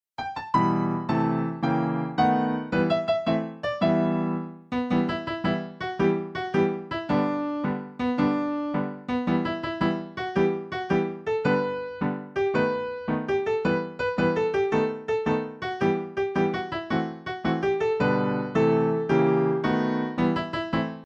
Traditional Patriotic Homecoming Song